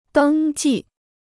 登记 (dēng jì) Free Chinese Dictionary